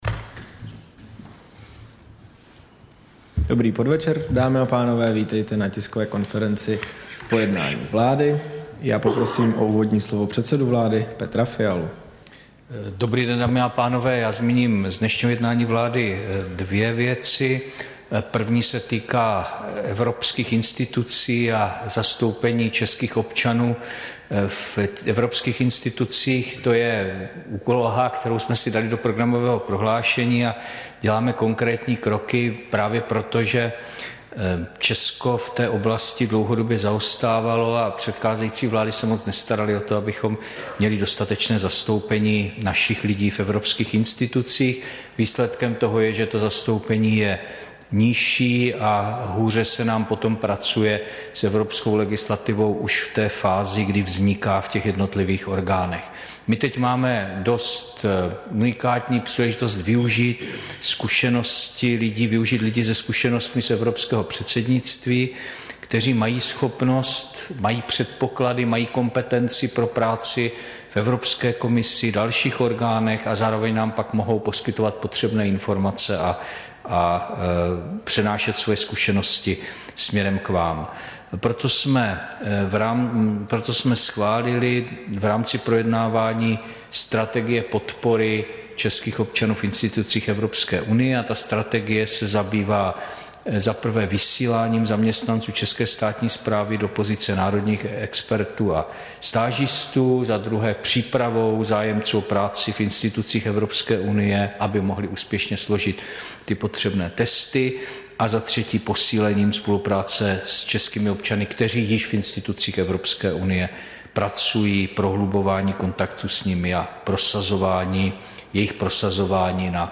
Tisková konference po jednání vlády, 5. dubna 2023